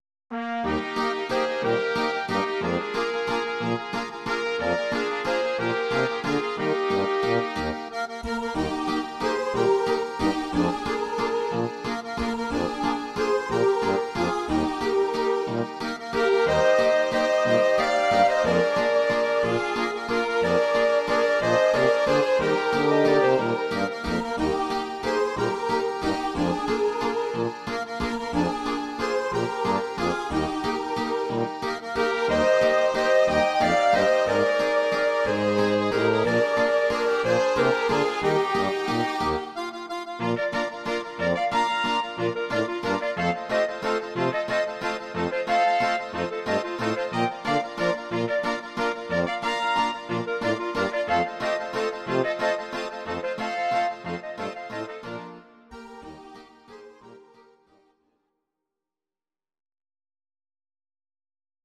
Audio Recordings based on Midi-files
German, 1960s